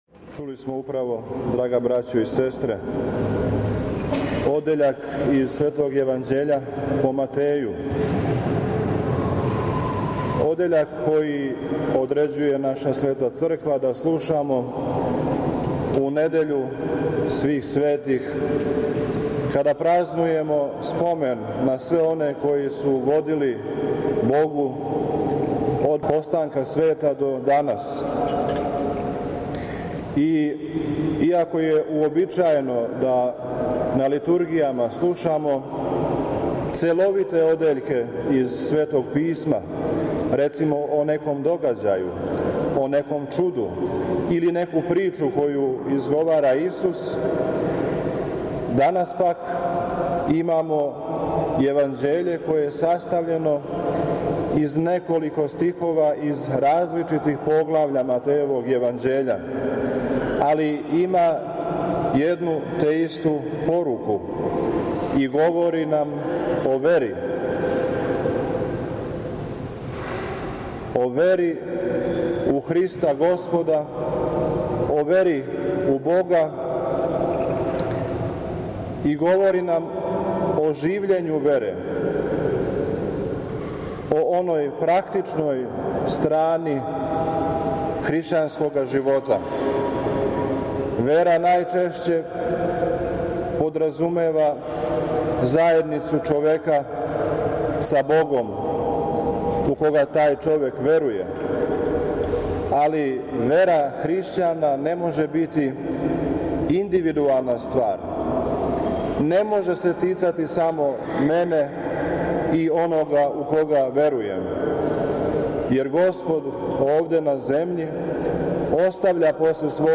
Његово Преосвештенство викарни Епископ марчански г. Сава је у недељу прву по Духовима, Свих Светих, 11. јуна 2023. године, служио свету Литургију у храму св. Апостола и Јеванђелисте Марка на Ташмајдану.
Звучни запис беседе